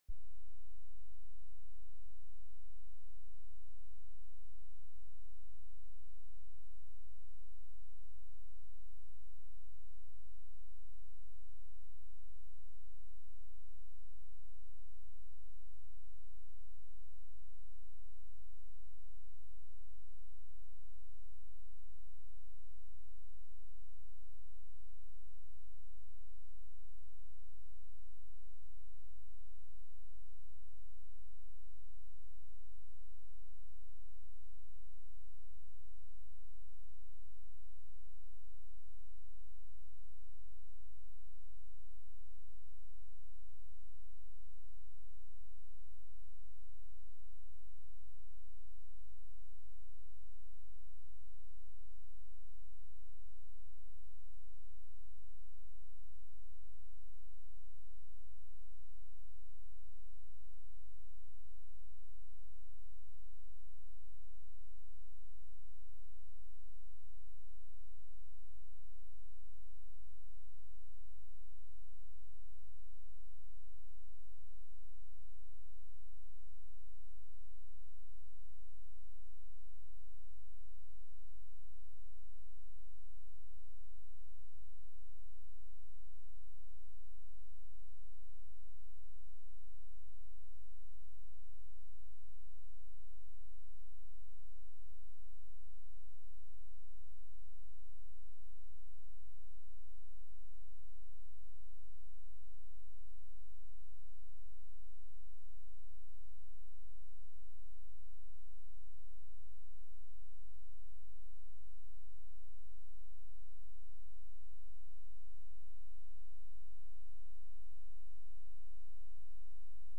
Sermons | Grace Lutheran Church
Sunday-Service-3-20-22.mp3